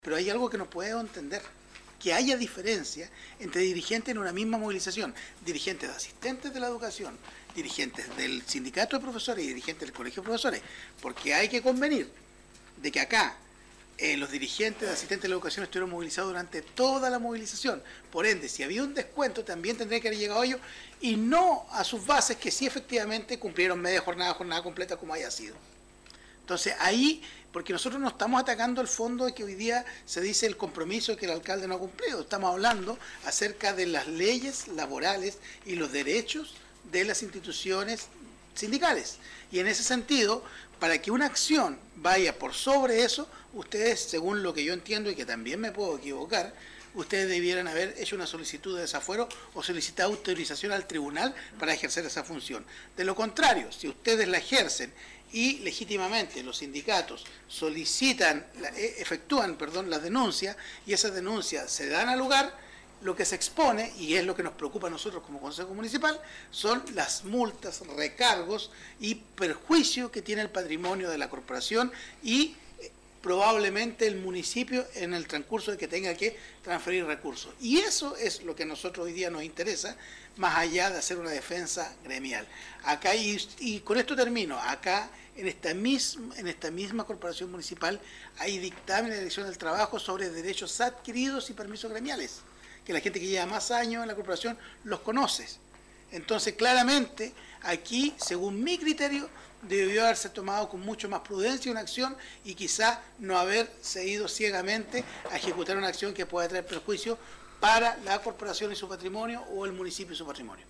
En esta misma línea, el concejal Andrés Ibáñez remarcó aún más lo irregular de la acción de la corporación que no entrega en su totalidad los recursos asignados desde la municipalidad y retrasa el término de esta crisis que tanto ha golpeado a la educación pública de Ancud.